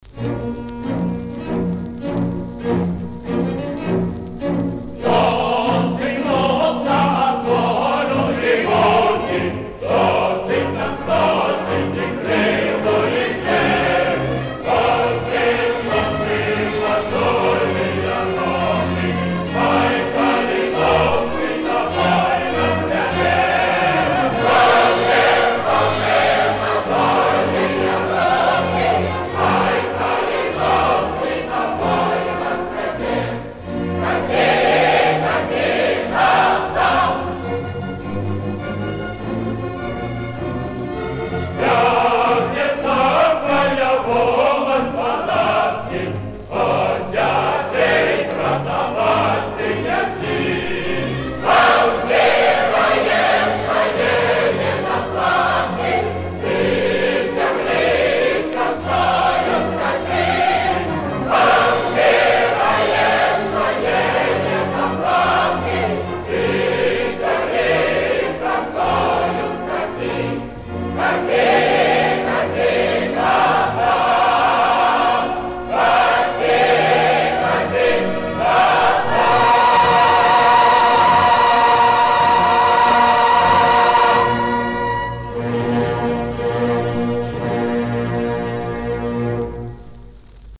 Грозная песня из оперы